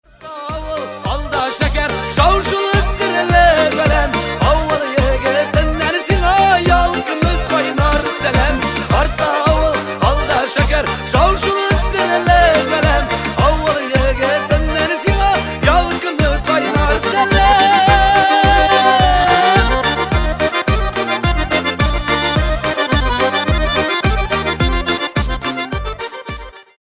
Kатегория: » Татарские рингтоны